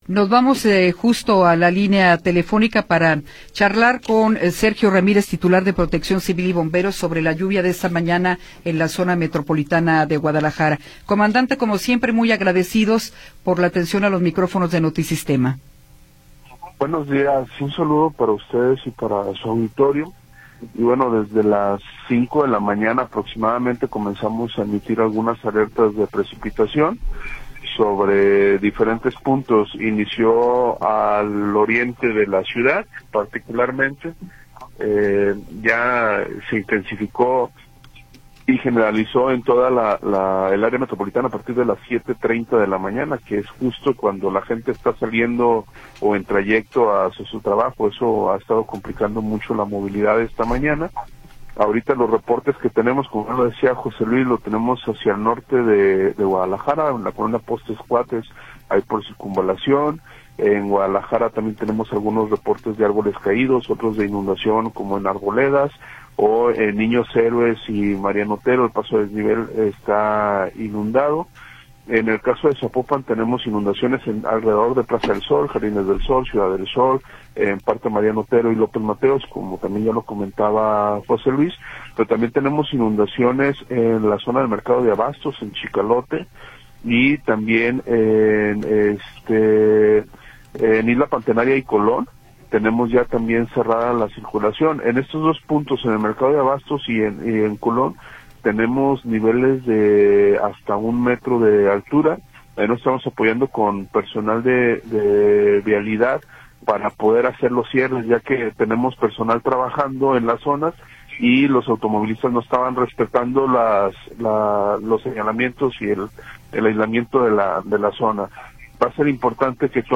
Entrevista con Sergio Ramírez López